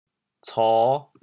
反切 清卧
国际音标 [ts]